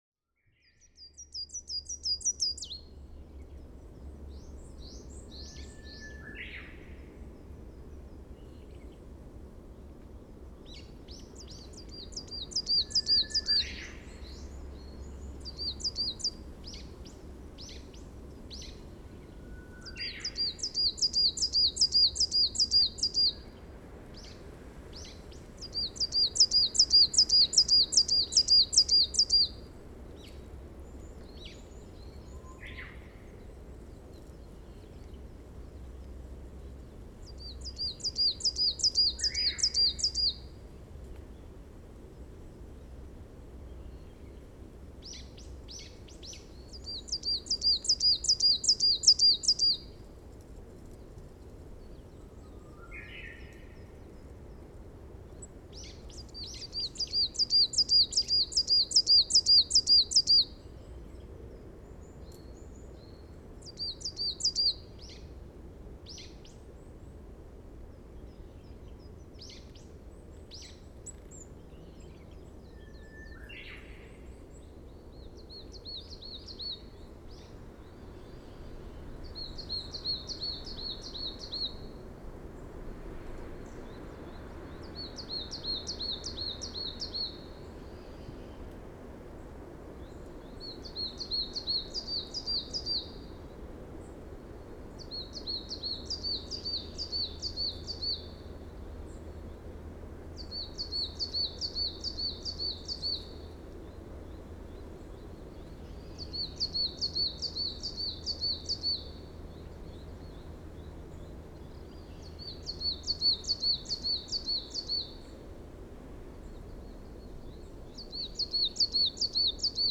Edirol R-09, Telinga DAT Stereo 2009年12月30日 宮城県大崎市
声でもコハクチョウと区別できます。オオハクチョウはラッパのような響きが強いというか・・・コハクチョウは庄内平野では少ないので、この時は嬉々として、わくわくしながらマイクを向けていたのをよく覚えています。
Telingaですがパラボラをはずしています。低域ノイズが少ないのでハイパスフィルターはオンにしているはず。オフにしたほうがオオハクチョウらしい響きがもっとあったんじゃないかな。